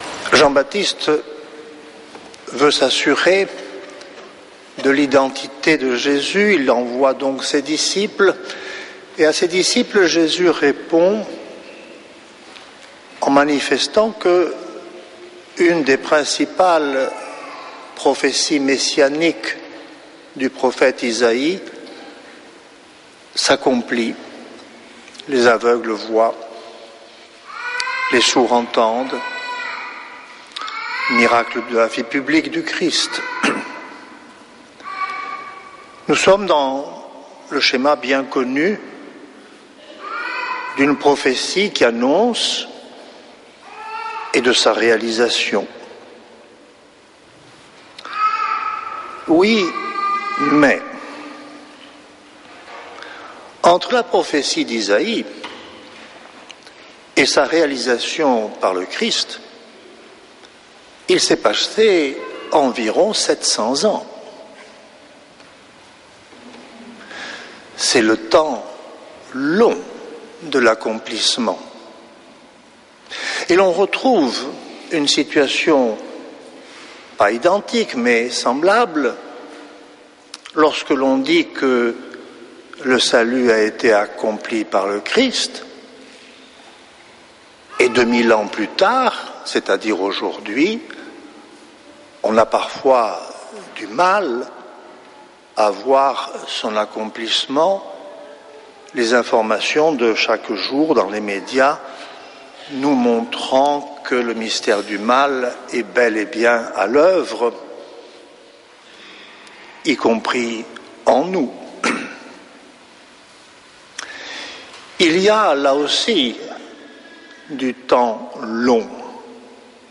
dimanche 14 décembre 2025 Messe depuis le couvent des Dominicains de Toulouse Durée 01 h 28 min